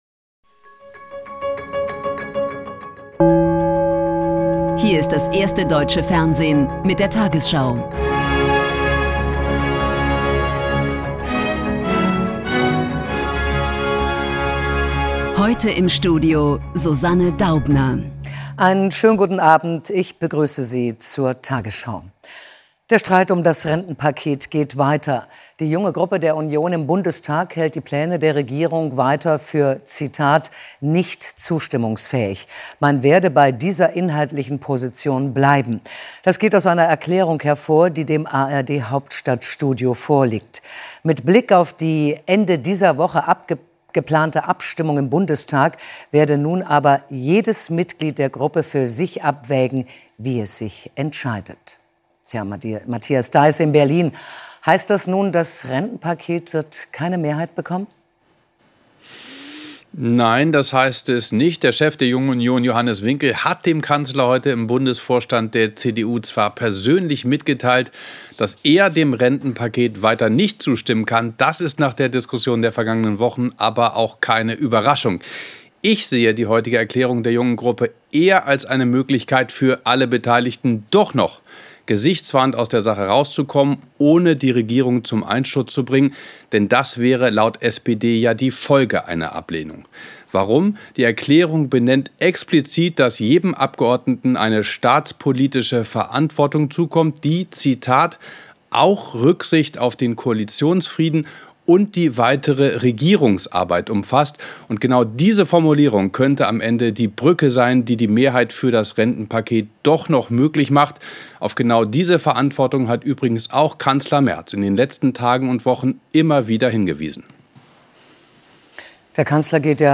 tagesschau 20:00 Uhr, 01.12.2025 ~ tagesschau: Die 20 Uhr Nachrichten (Audio) Podcast